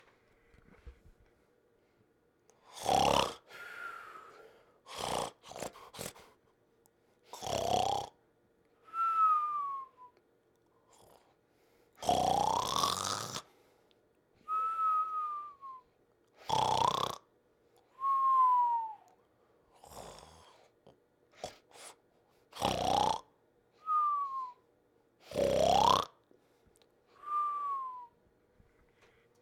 Big Snore
big sleep sleeping snore snoring sound effect free sound royalty free Sound Effects